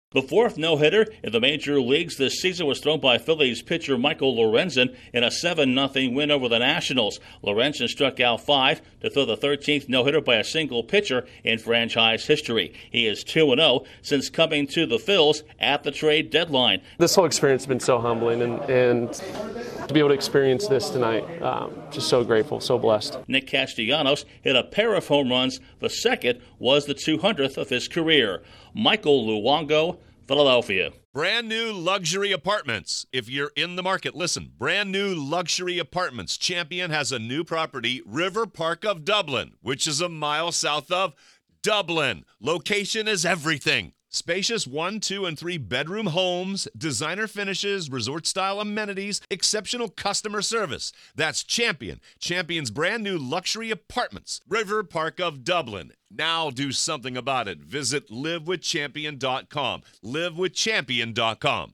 A Phillies newcomer makes history in a rout of the Nationals. Correspondent